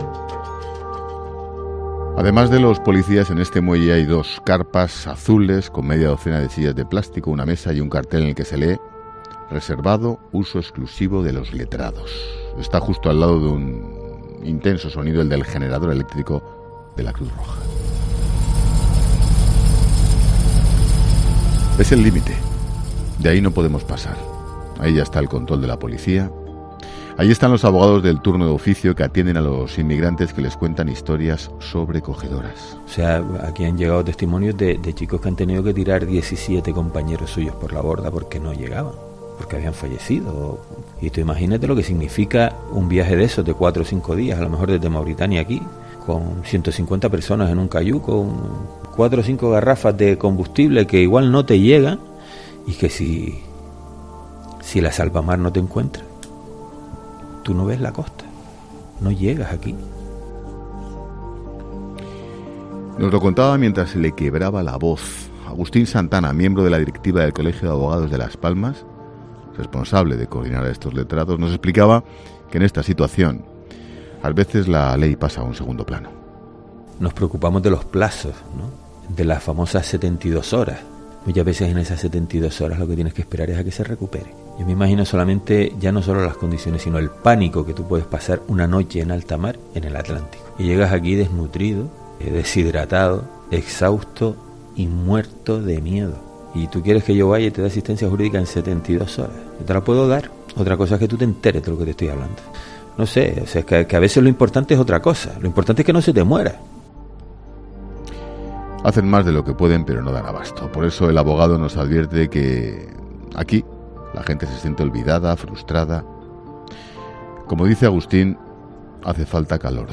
Está justo al lado de un intenso sonido. El del generador eléctrico de la Cruz Roja.
A tan sólo 50 metros del muelle de Arguineguín te hemos contado la auténtica realidad de lo que está pasando en el sur de la frontera sur de Europa.